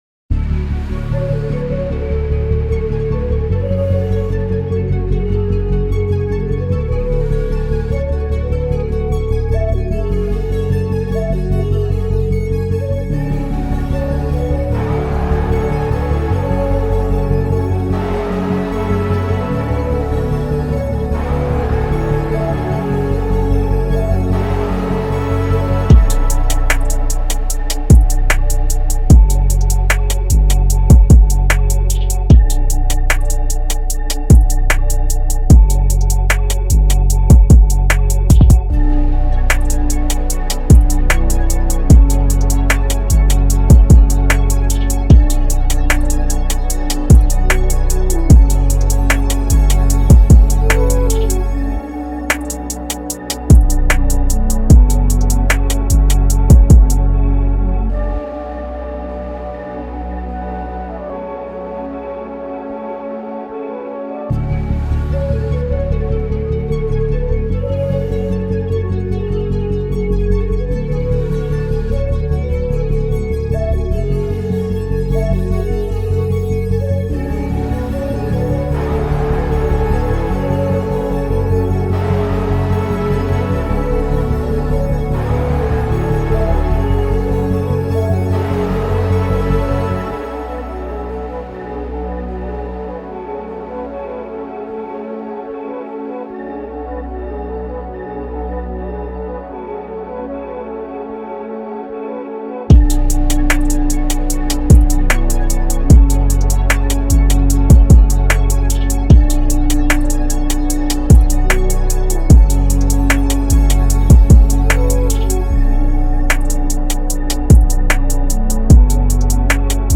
Here's the official instrumental
2022 in Hip-Hop Instrumentals